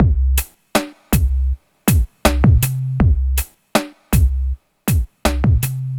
Downtempo 27.wav